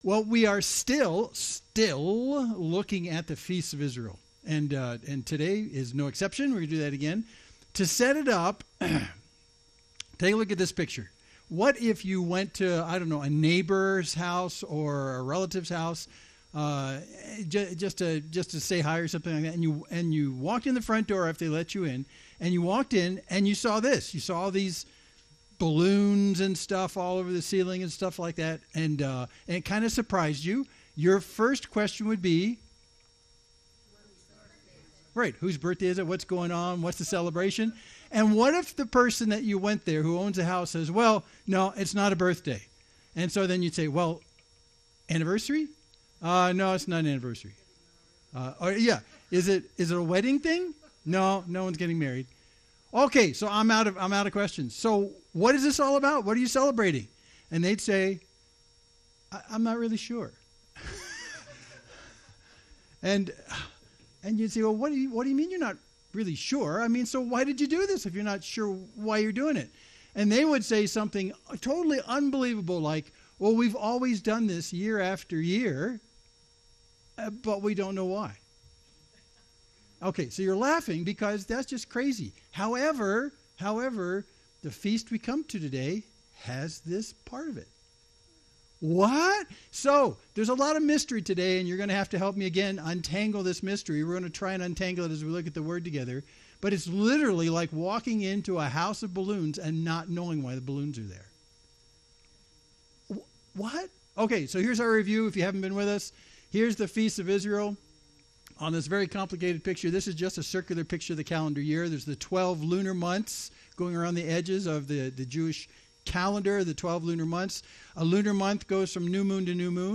Main Street Church Sermon (17.09 - )